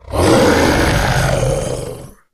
izlome_attack_5.ogg